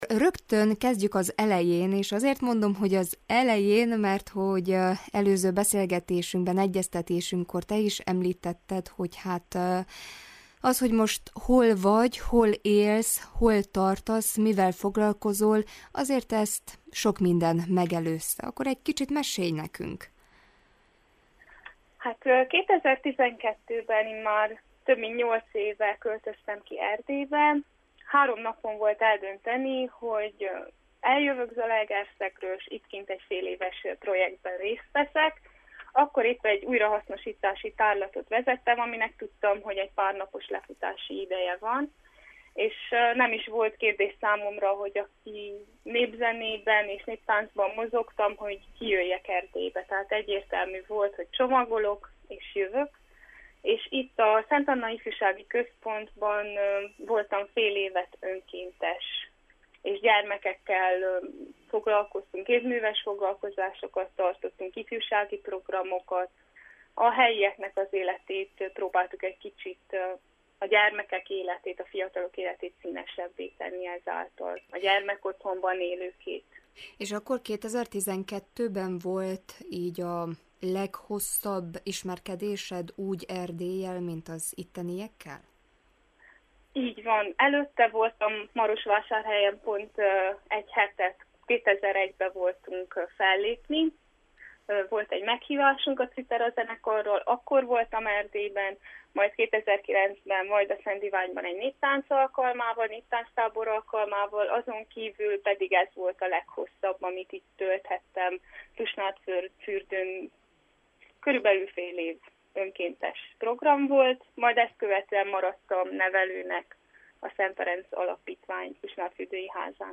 Megéri végig hallgatni beszélgetésünket, hiszen befejezésképpen két gyönyörű dallal is megajándékoz bennünket. https